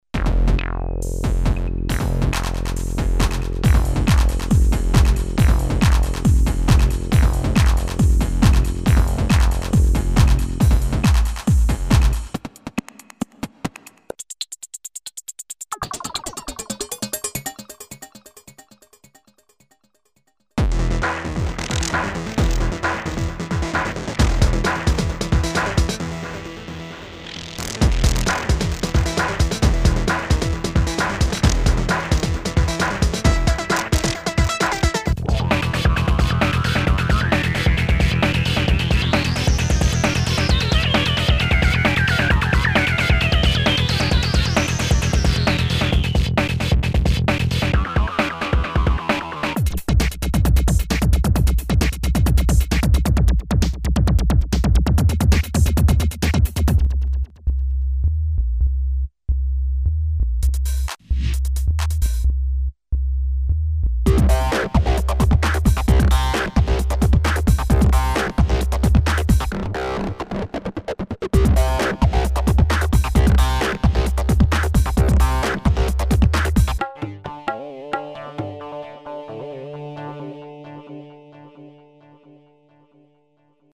Mostly DJ-Live oriented in the LOOP FACTORY series grooveboxes based on AN analog physical modeling synthesis and sampled percussions.